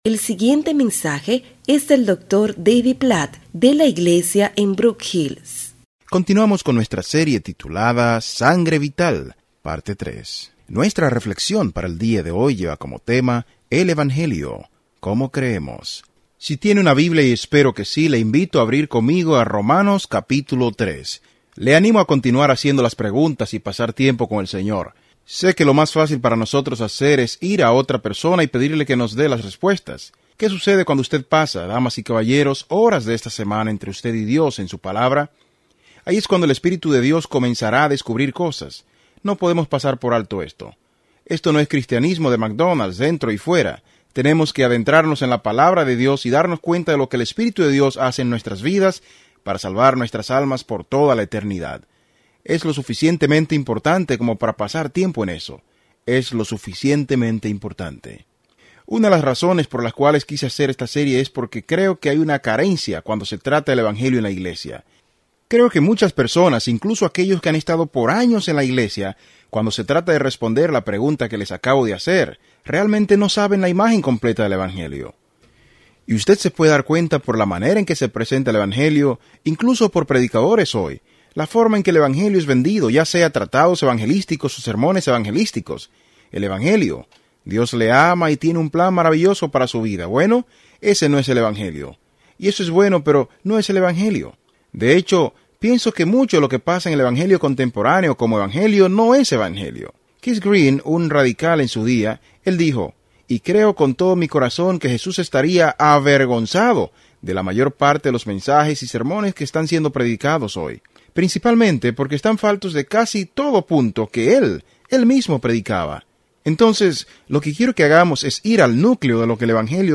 En este mensaje sobre Romanos 3:21–26